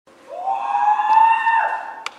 Goofy Ahh Scream Funny